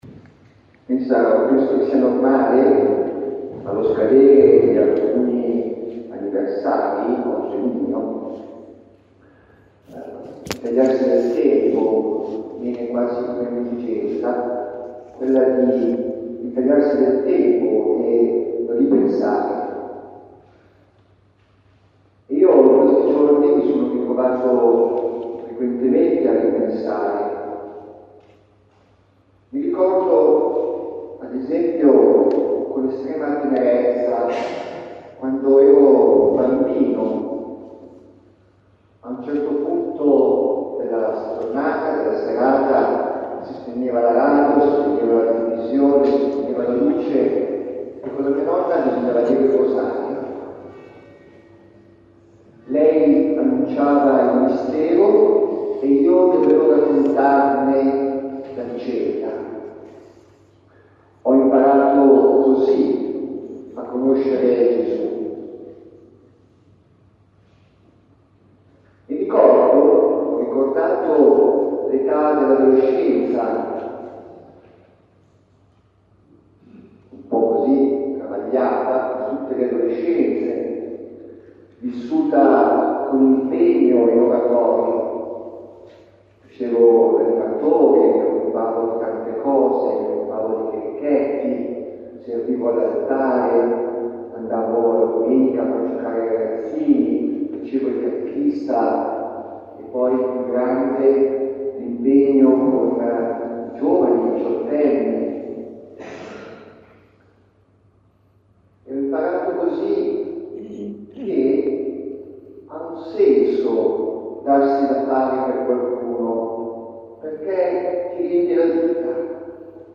Omelia della Celebrazione Eucaristica in occasione del XV anniversario di Ordinazione Presbiterale